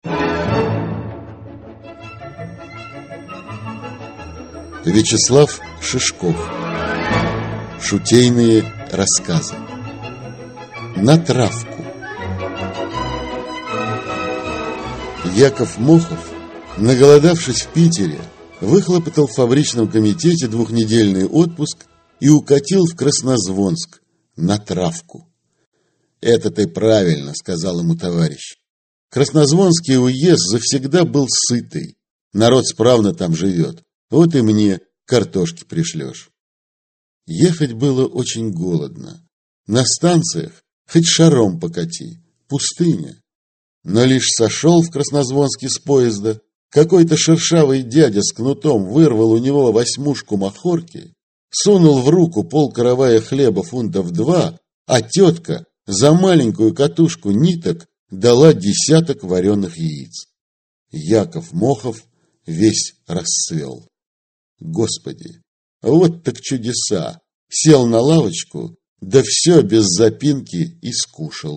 Аудиокнига Шутейные рассказы (сборник) | Библиотека аудиокниг